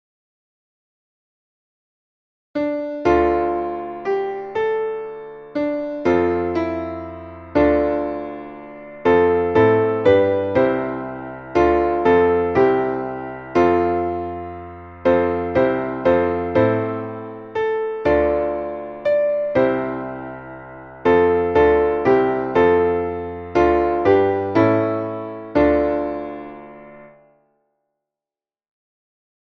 Traditionelles Advents-/ und Weihnachtslied